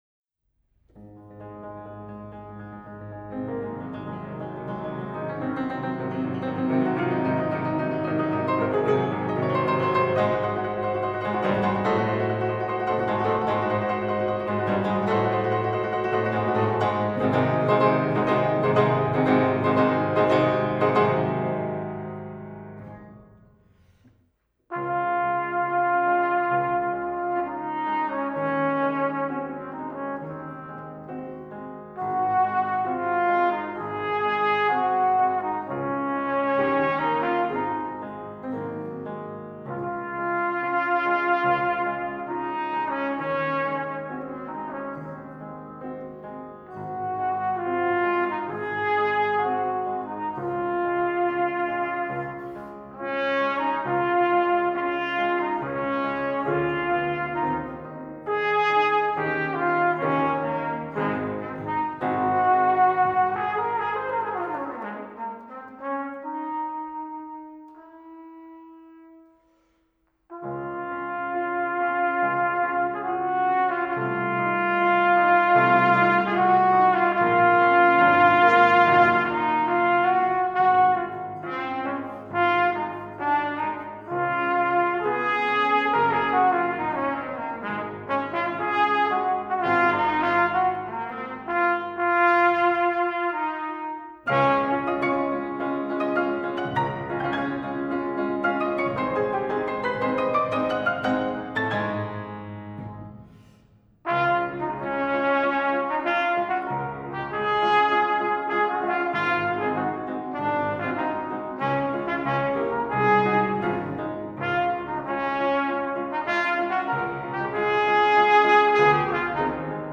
Recital